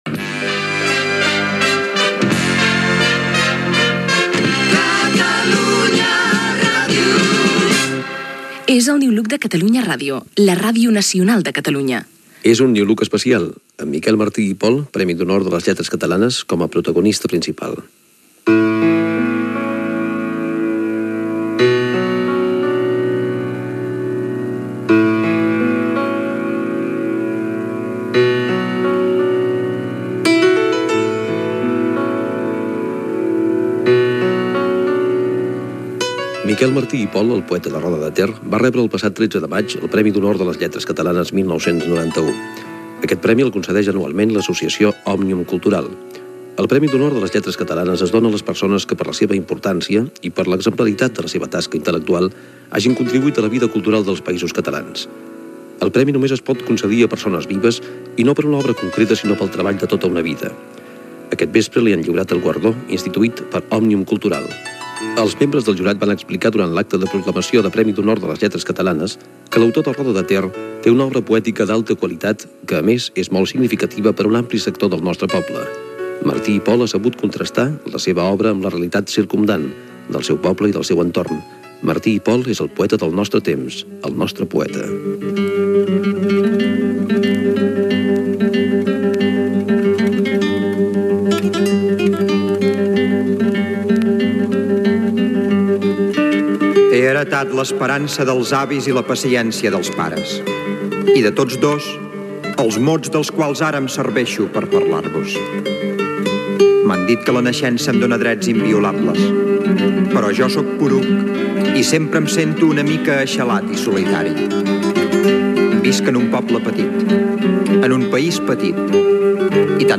Indicatiu del programa, edició especial amb motiu del lliurament del Premi d'Honor de les Lletres Catalanes al poeta Miquel Martí i Pol. Repàs a la vida i l'obra de l'escriptor